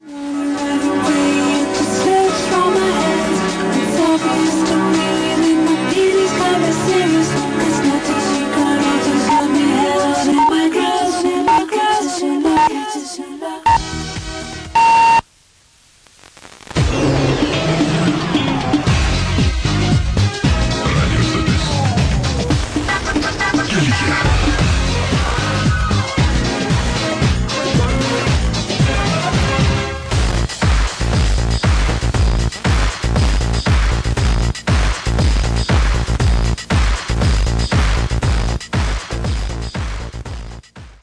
Here a few examples of stations-id's and reception qualities from Utö: